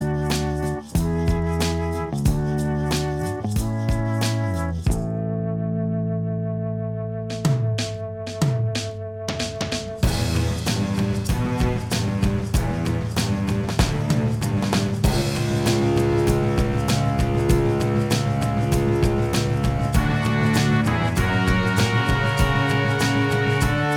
Minus Guitars Pop (1960s) 3:34 Buy £1.50